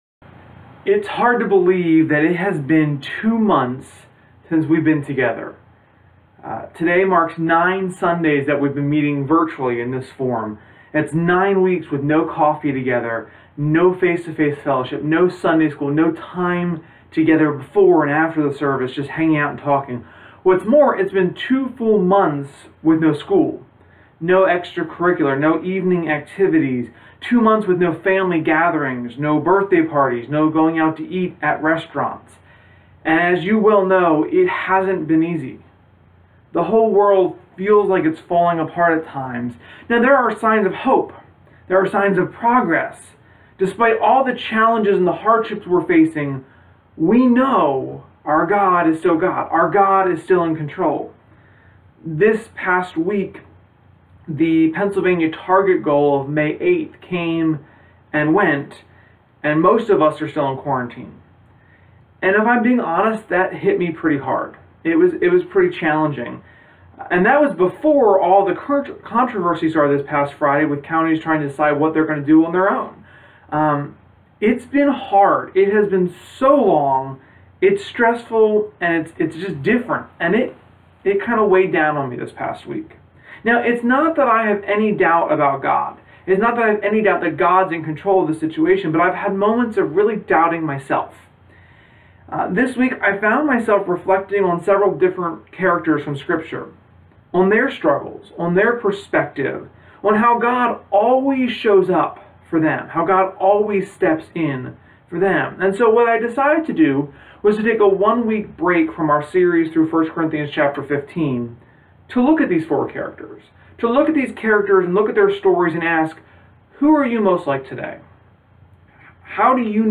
Sermon-5-10-20.mp3